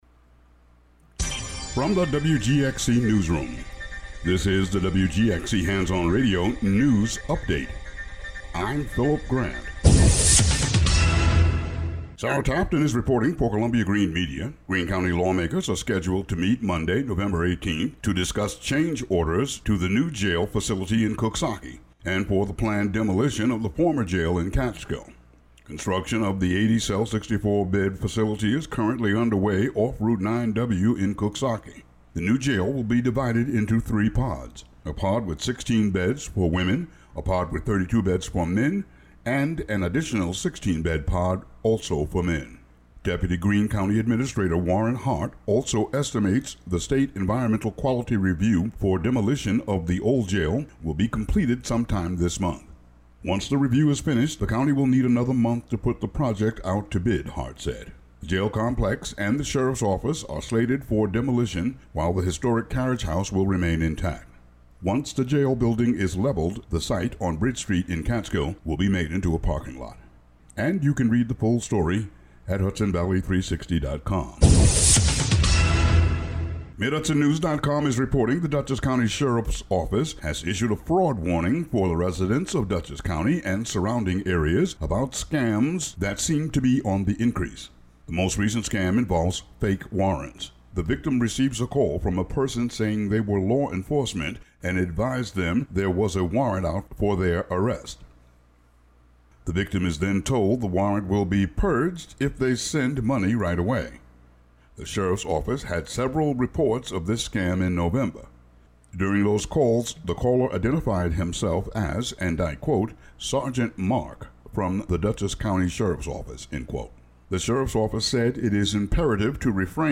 WGXC Local News Update Audio Link